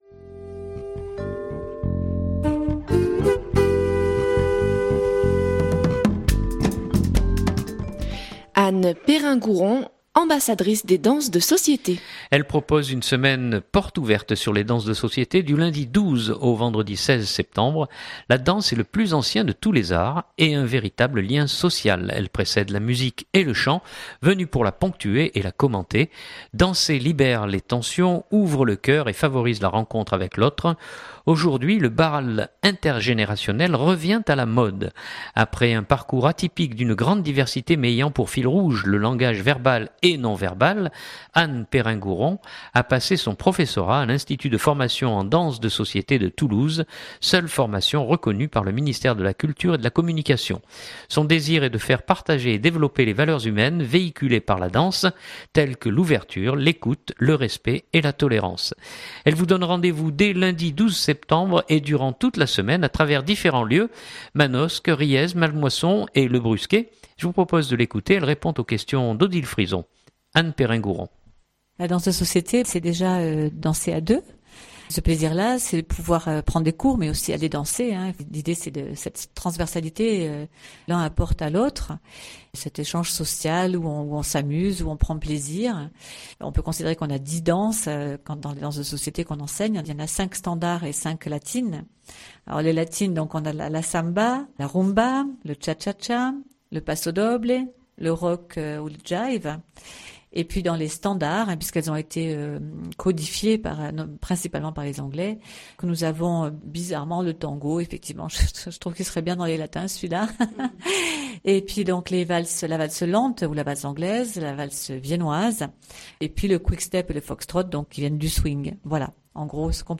Elle répond aux questions